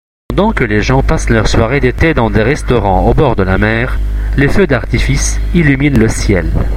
Podcast_Feu_Artifice.mp3 (108.18 Ko)